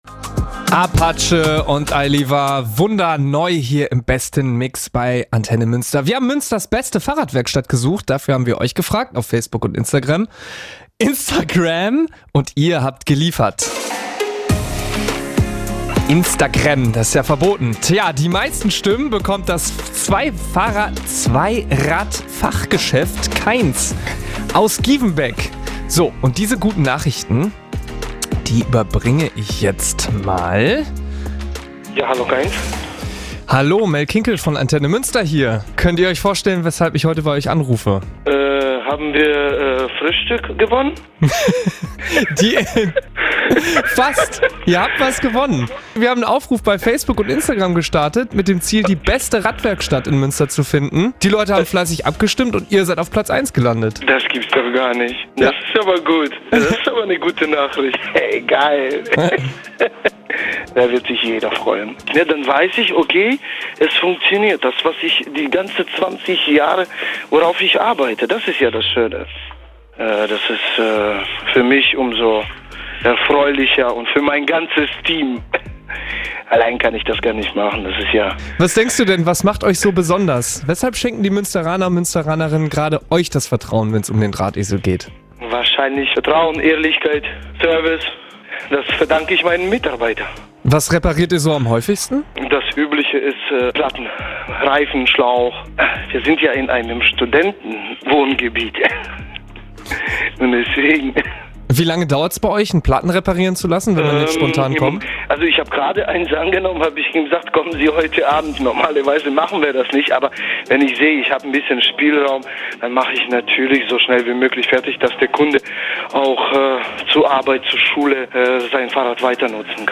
im ANTENNE MÜNSTER-Interview